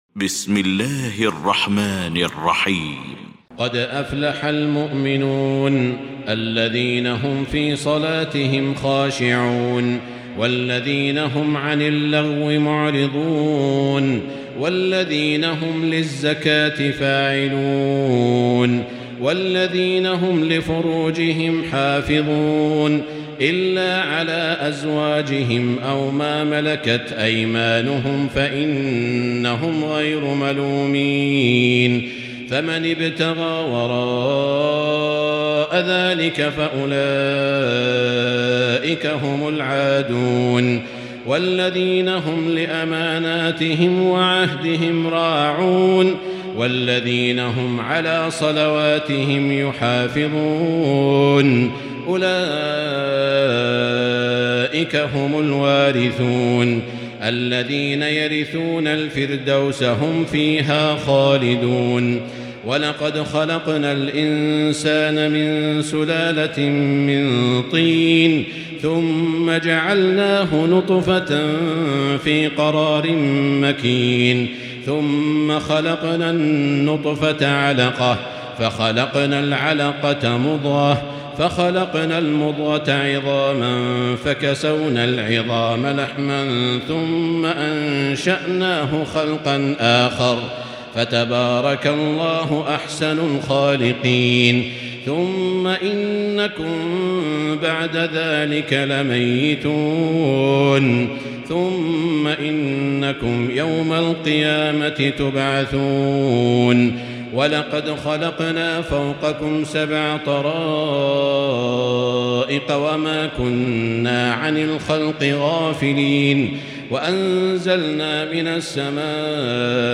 المكان: المسجد الحرام الشيخ: سعود الشريم سعود الشريم فضيلة الشيخ ماهر المعيقلي المؤمنون